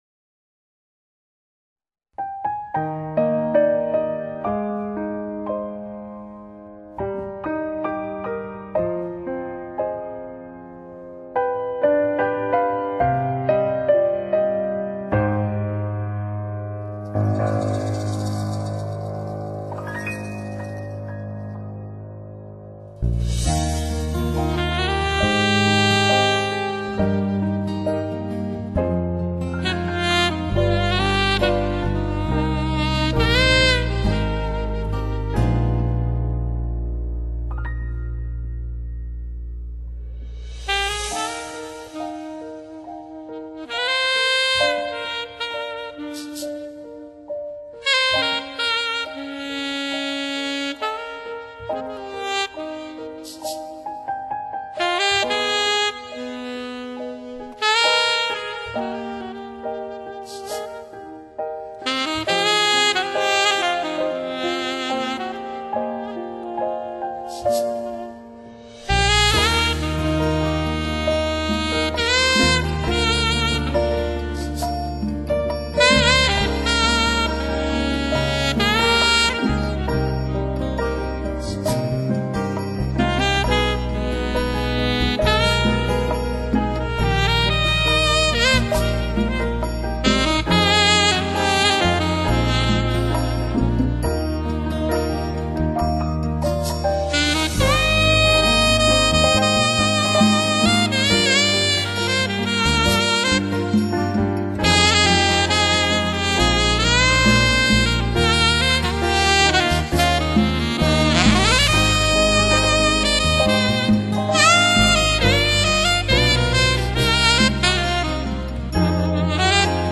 试音典范 超级音响体验
音乐版